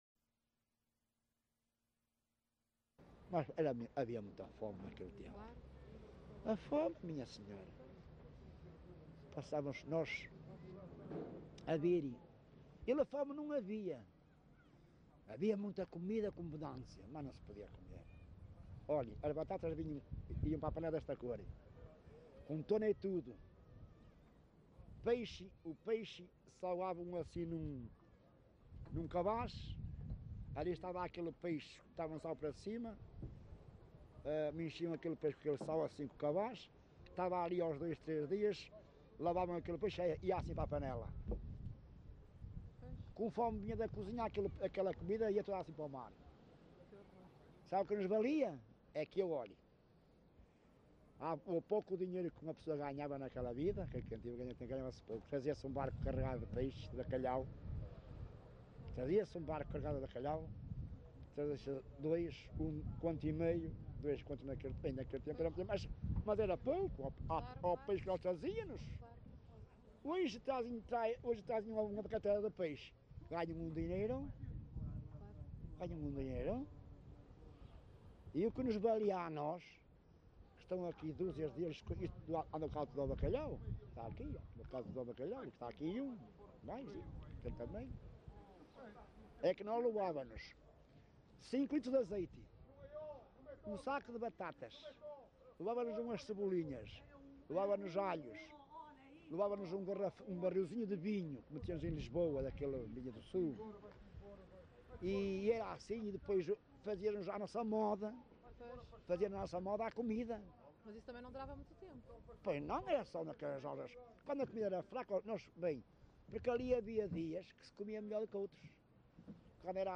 LocalidadeVila Praia de Âncora (Caminha, Viana do Castelo)